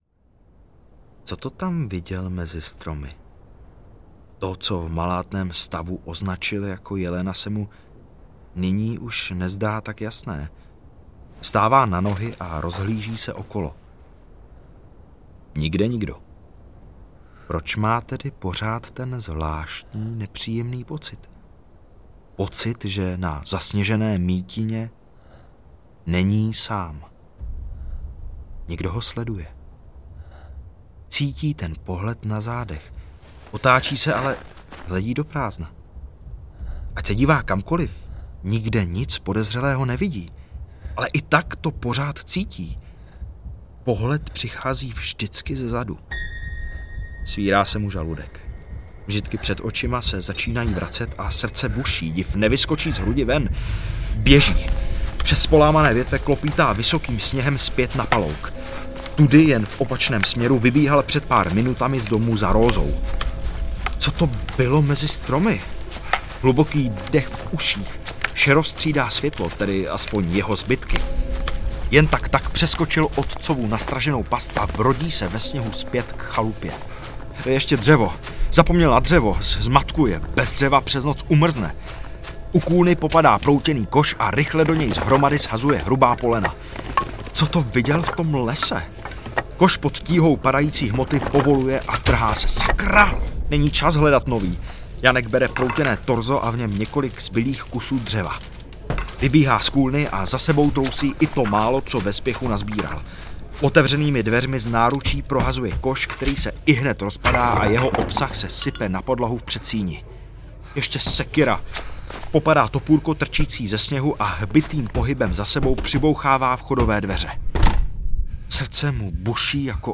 Klekání audiokniha
Ukázka z knihy